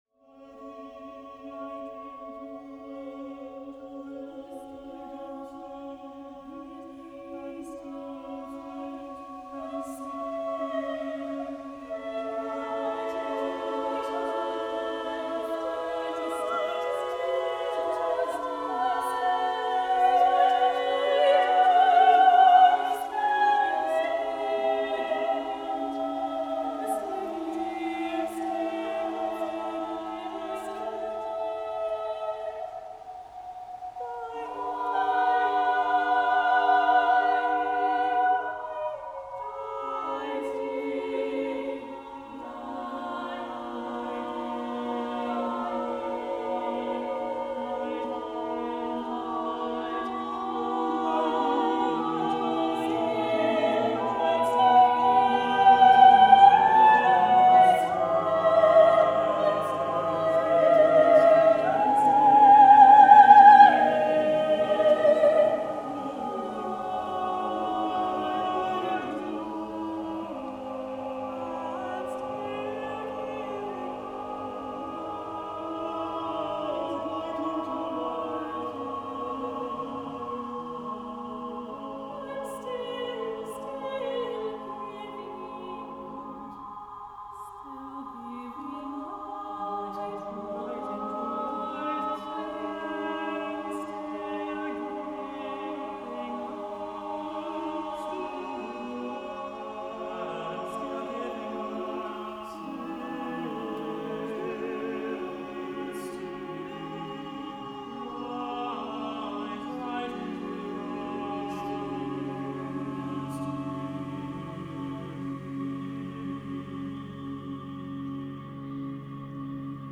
contemporary American music